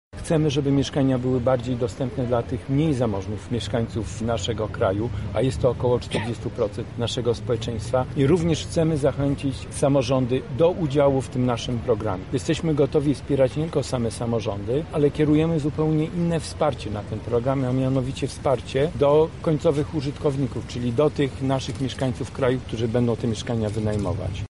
O Mieszkaniu + mówi Jerzy Kwieciński, minister inwestycji i rozwoju: